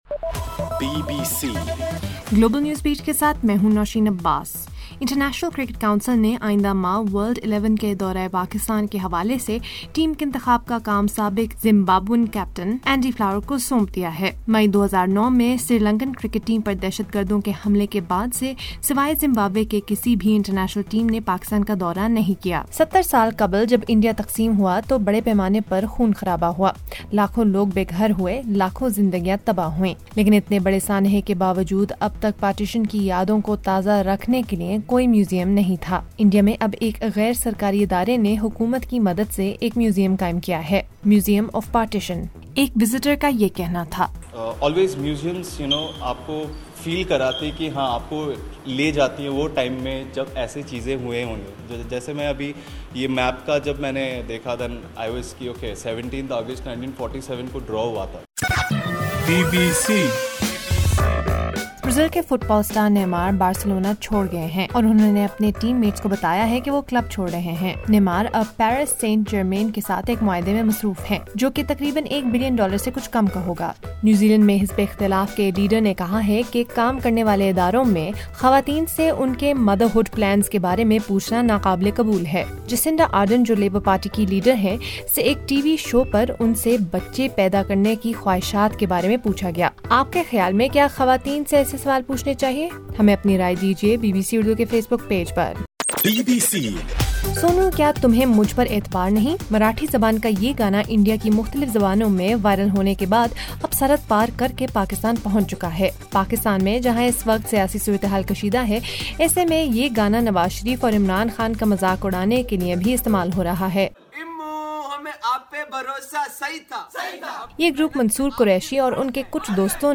اگست 02 : شام 10 بجے کا نیوز بُلیٹن